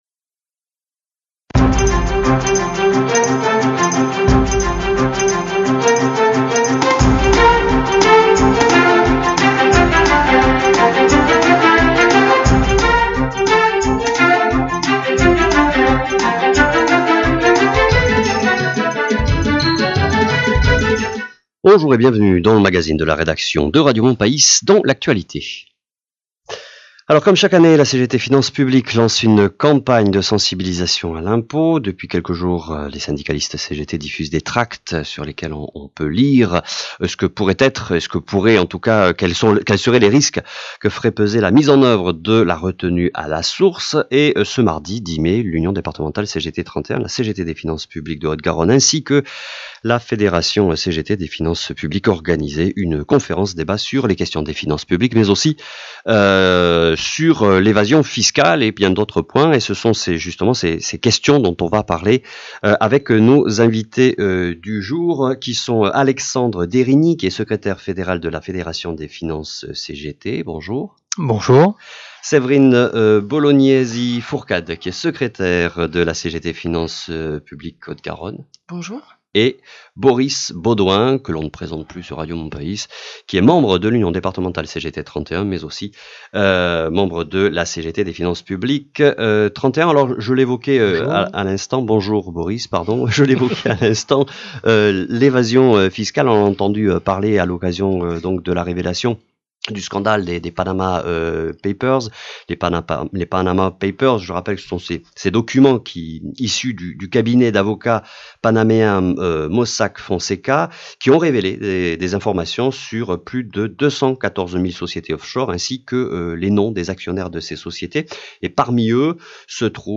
Une émission de radio a été enregistrée dans les studio de la radio toulousaine Mon Païs.